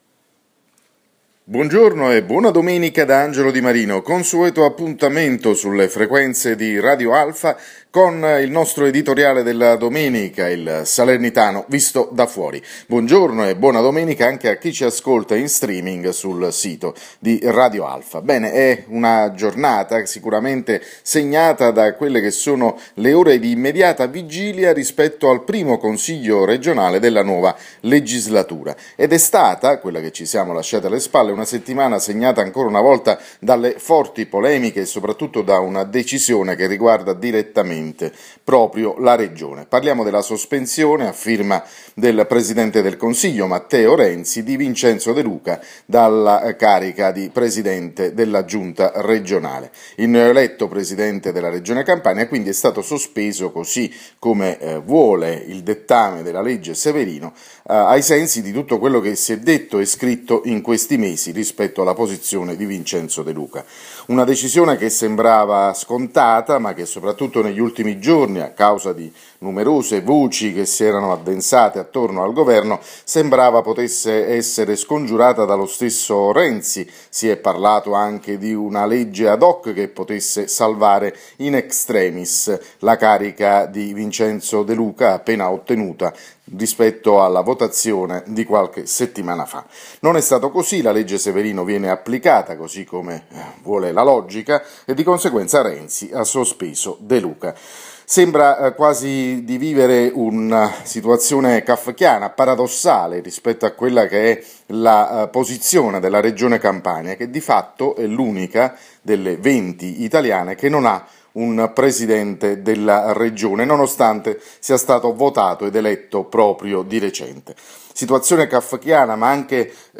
L’editoriale della domenica andato in onda sulle frequenze di Radio Alfa questa mattina.